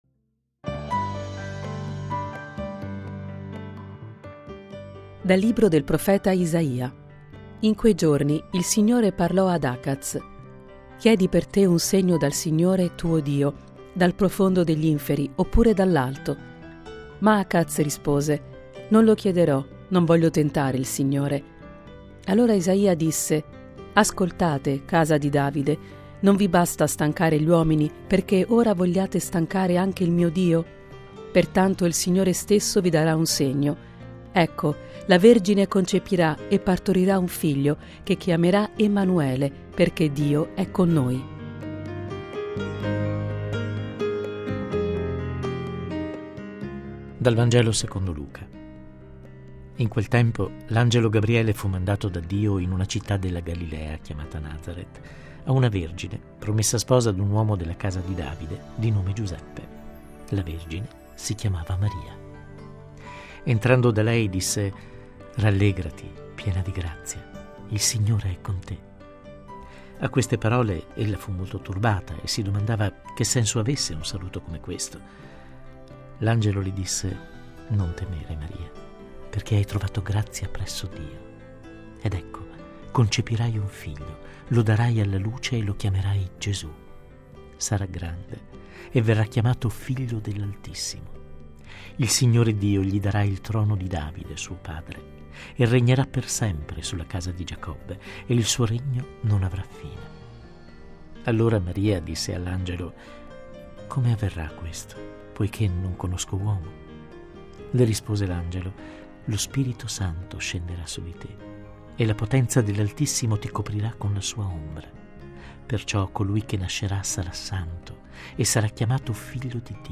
Le letture del giorno (prima e Vangelo) e le parole di Papa Francesco da VaticanNews.